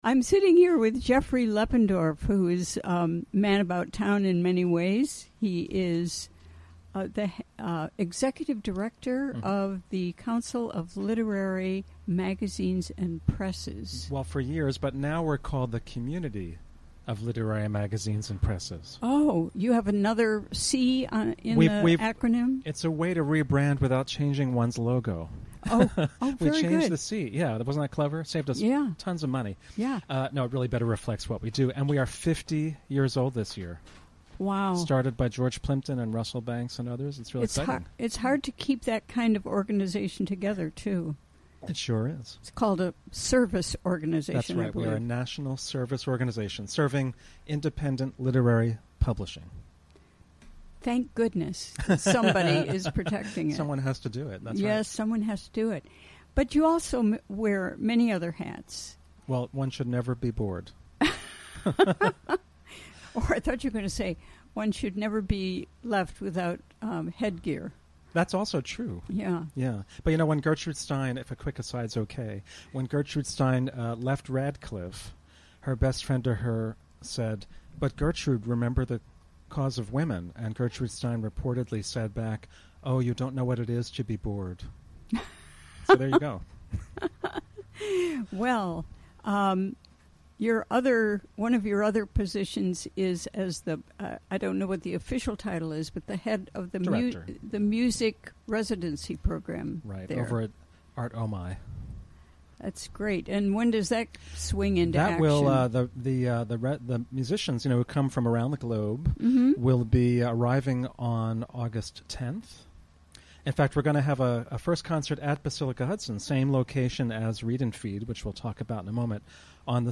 Recorded during the WGXC Afternoon Show on Thursday, July 20, 2017.